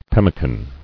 [pem·mi·can]